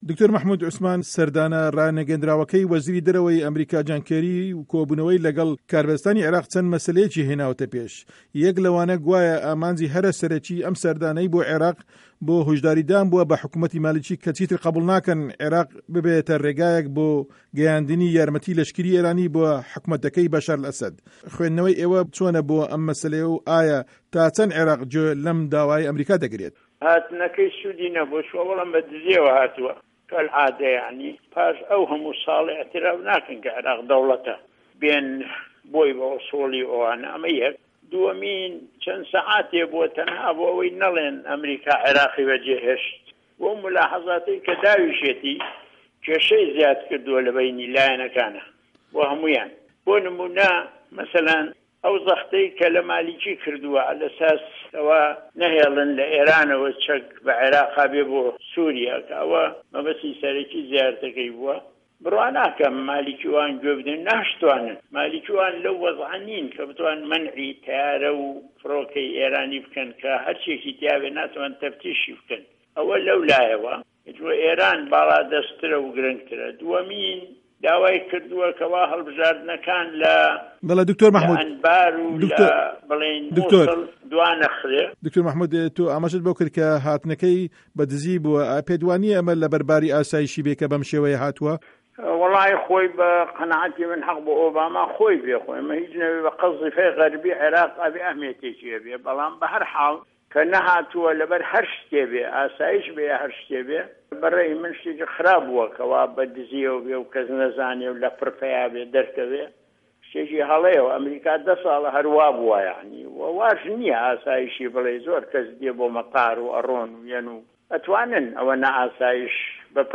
وتووێژی مه‌حمود عوسمان